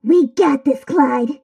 bonni_start_vo_08.ogg